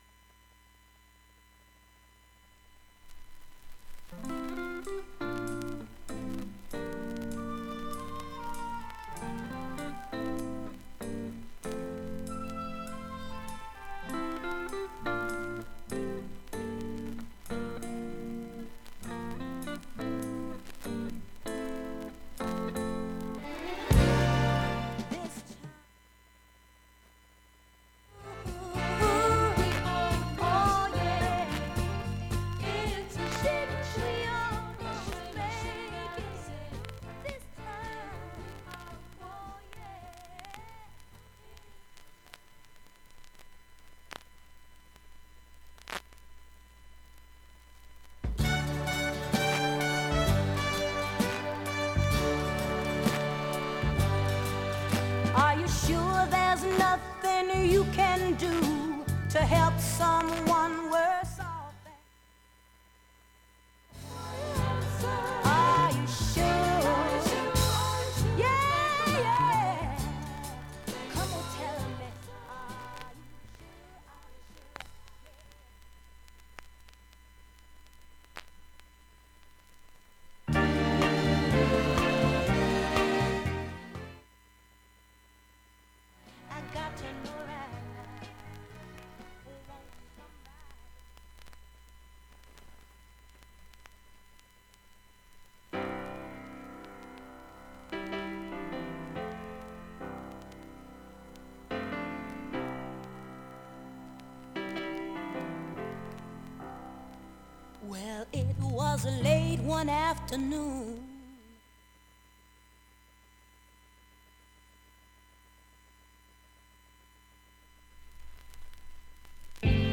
A面は静かな部などで周回でシャリ音聴こえますが、
両面ともに普通に音質はいいと思います全曲試聴済み。
現物の試聴（上記ダイジェスト録音時間4分）できます。
サザンレディスイートソウル名盤